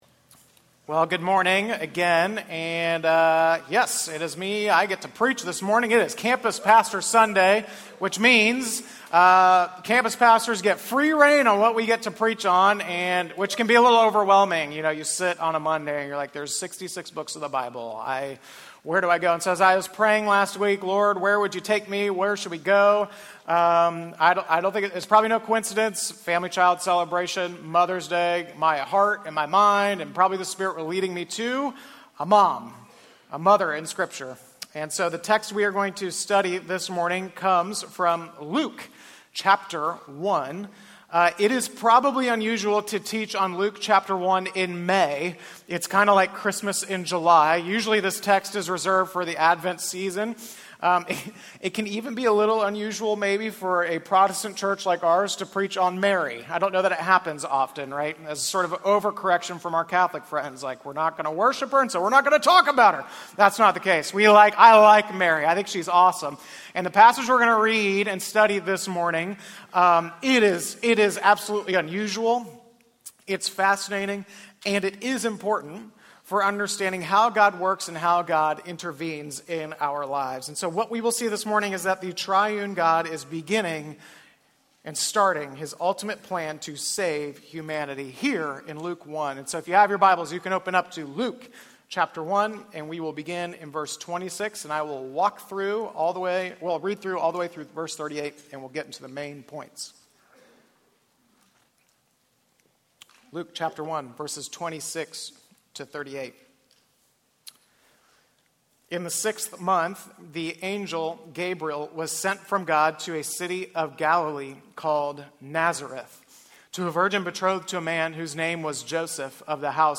Sermons
Baptism Sunday (Independence)